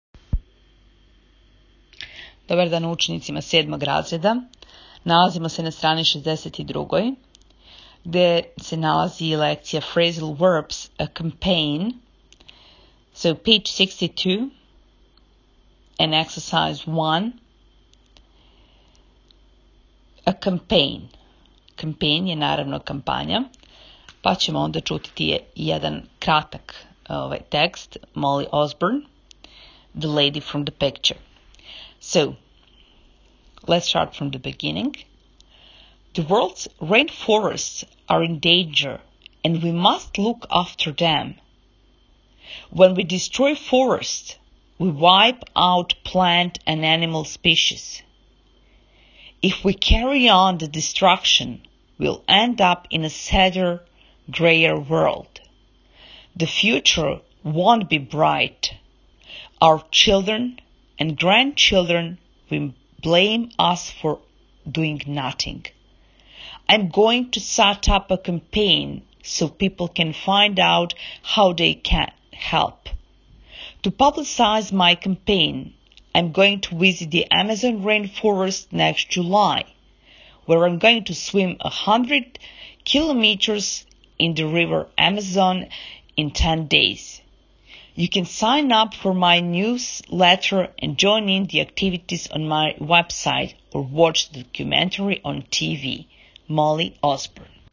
Читање:
7.raz_.-čitanje-A-Campaign.m4a